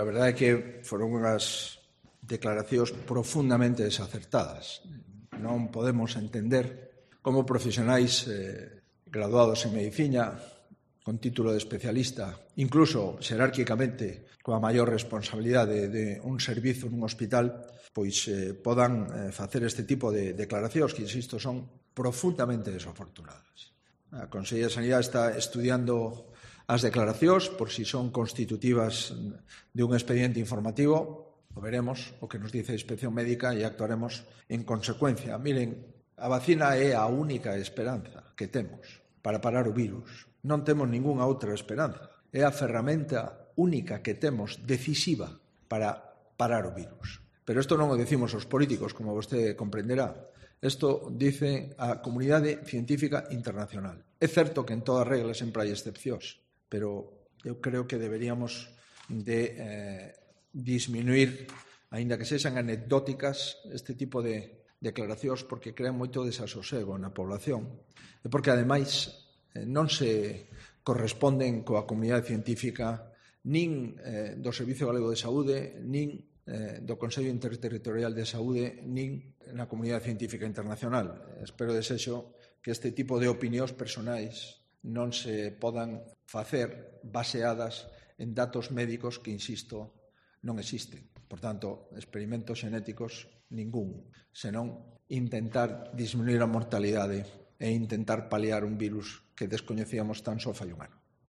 Así lo señaló el mandatario gallego en la rueda de prensa posterior al Consello de la Xunta celebrado hoy.